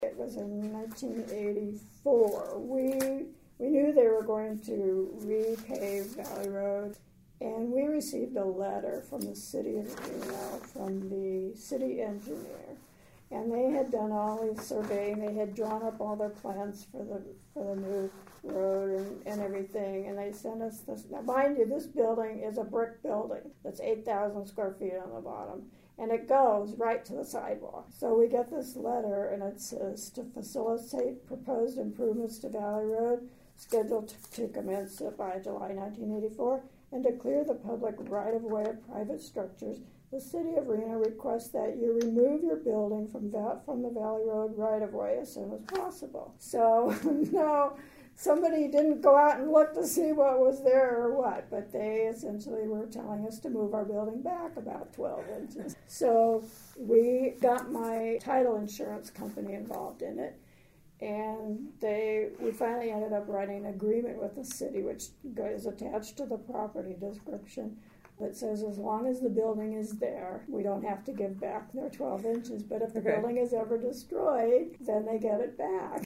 University of Nevada Oral History Program